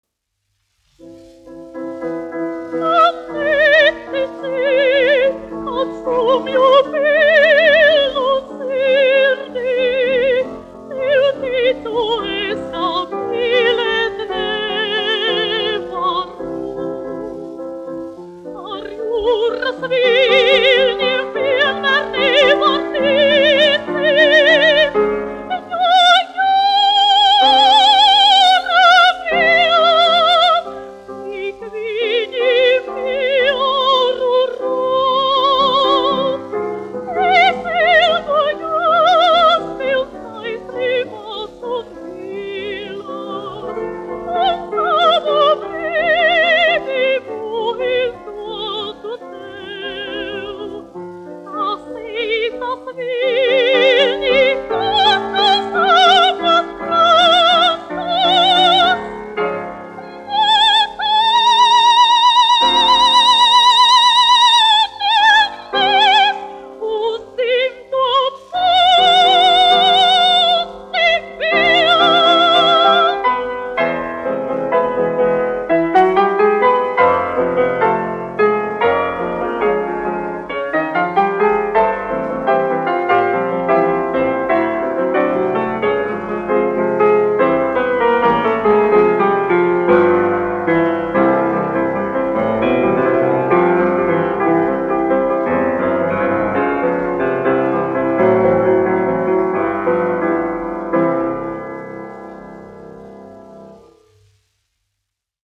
Žermēna Heine-Vāgnere, 1923-2017, dziedātājs
1 skpl. : analogs, 78 apgr/min, mono ; 25 cm
Dziesmas (augsta balss) ar klavierēm
Latvijas vēsturiskie šellaka skaņuplašu ieraksti (Kolekcija)